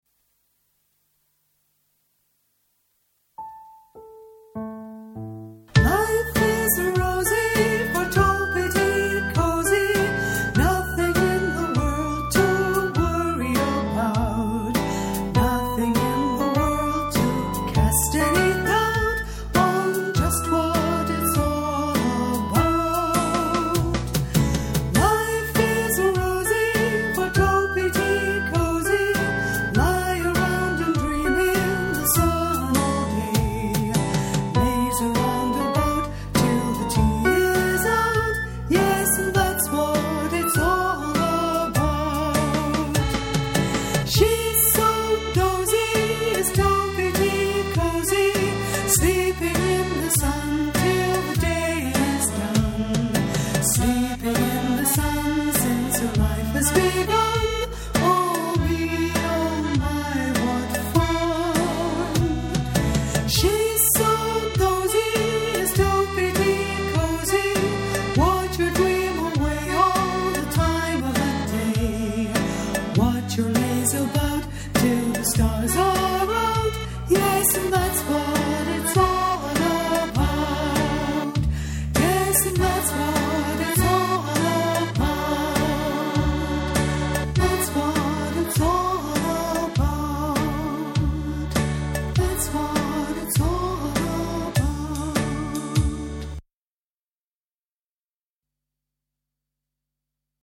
Fun-time songs for young children
Vocal, Piano, ideas for un-tuned percussion,
mp3 backing tracks downloads